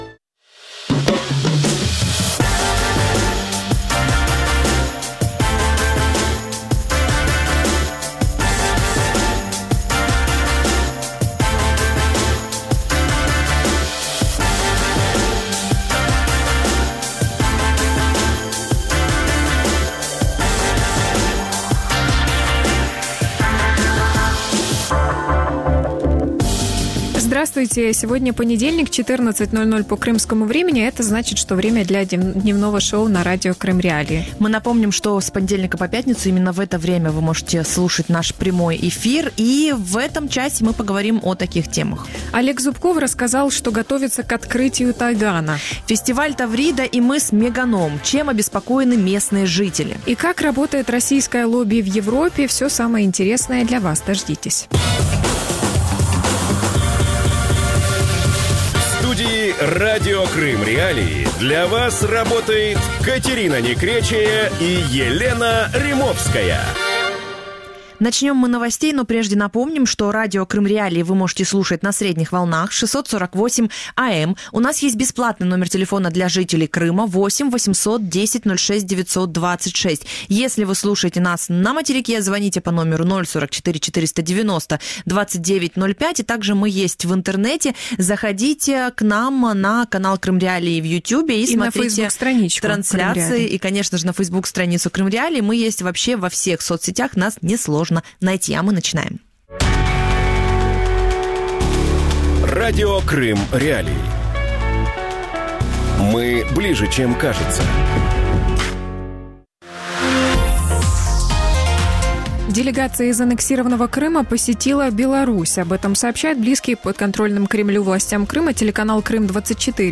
Судак. Мыс Меганом под угрозой | Дневное ток-шоу